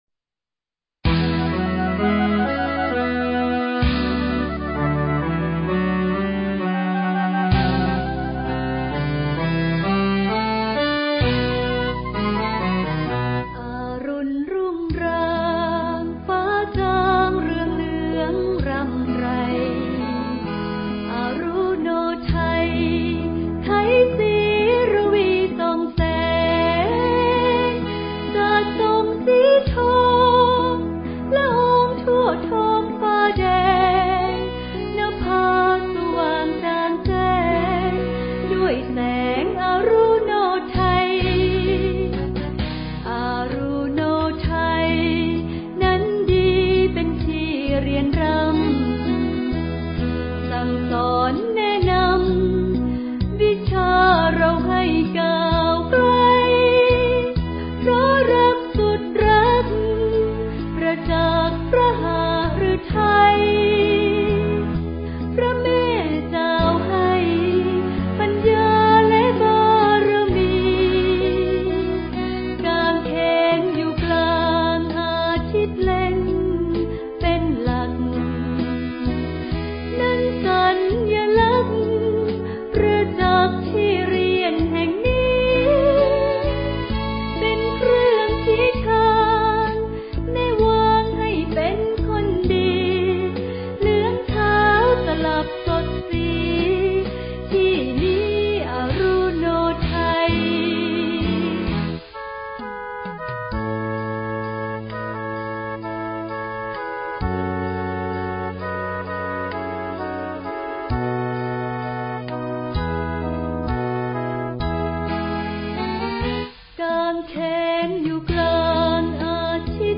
คีย์ผู้หญิง